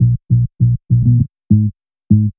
FK100BASS2-L.wav